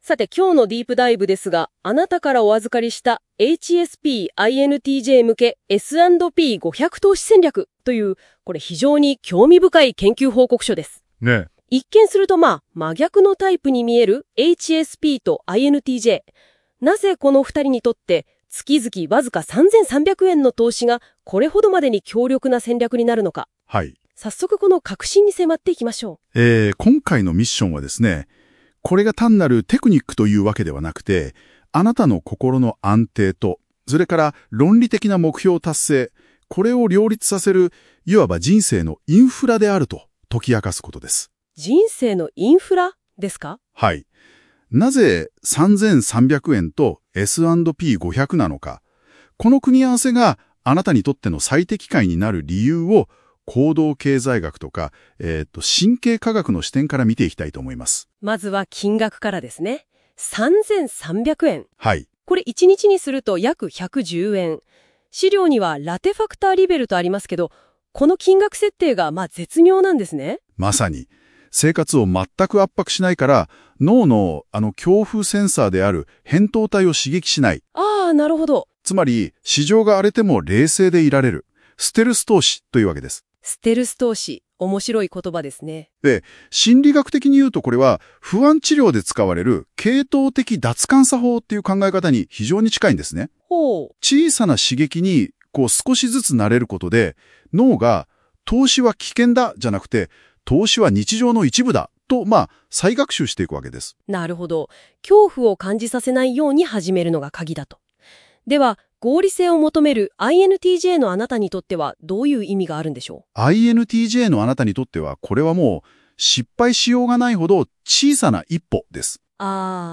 【音声解説】HSPとINTJのための月3300円S&P500戦略